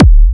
Kick OS 19.wav